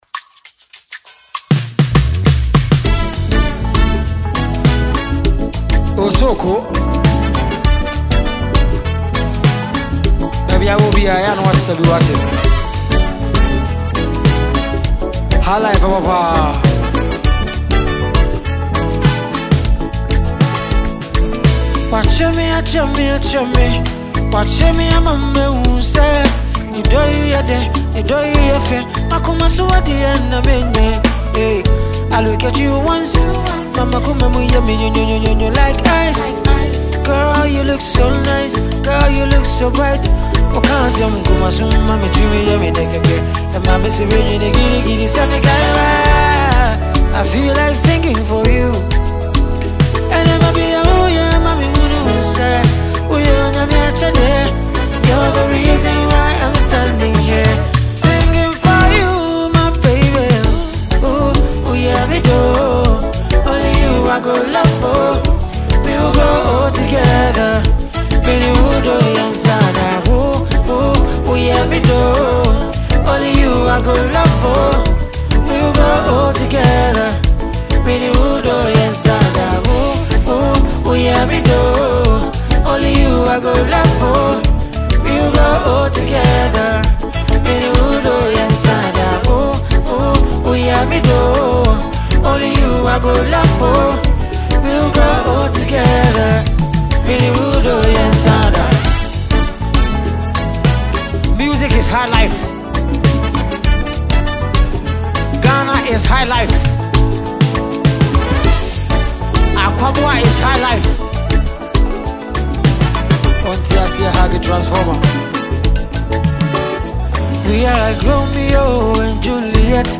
a Ghanaian highlife singer, producer, and songwriter
and this is a live performance.